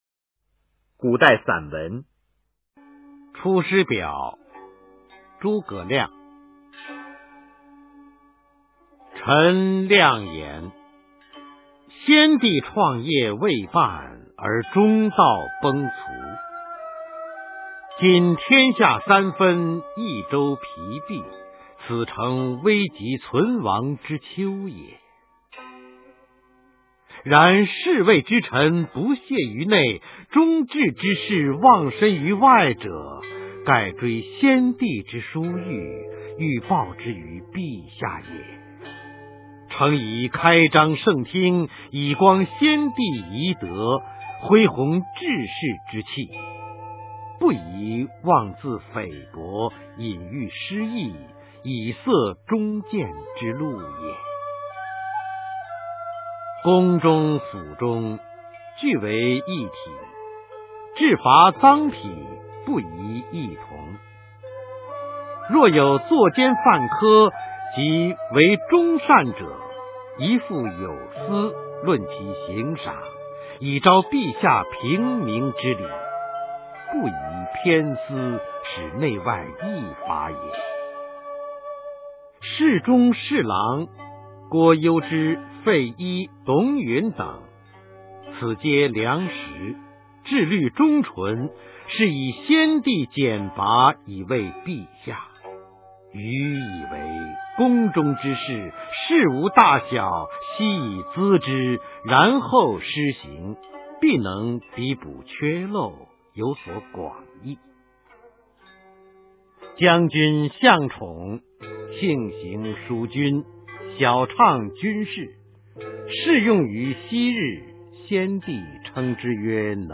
《出师表》原文和译文（含在线朗读）